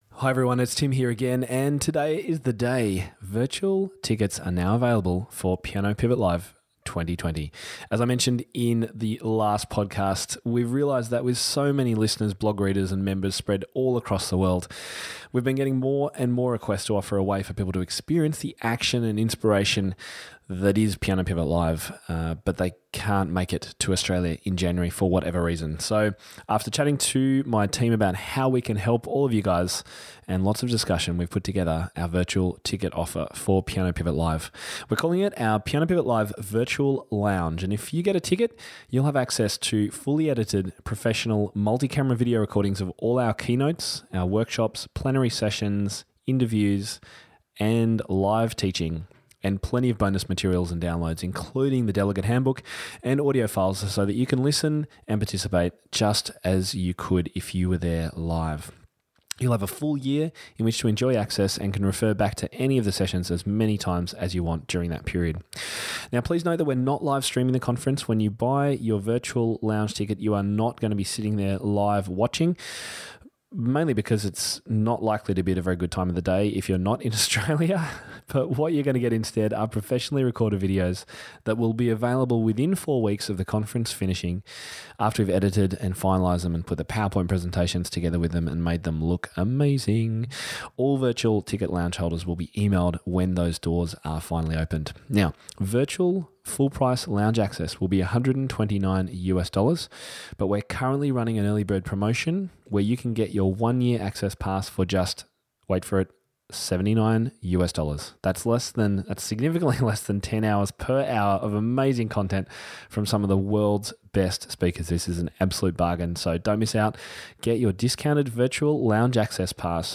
It's a super inspiring interview and I can't wait to share it with you.